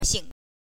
xingxing1 xing5star in the sky
xing5.mp3